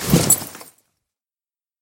sounds / mob / horse / armor.mp3
armor.mp3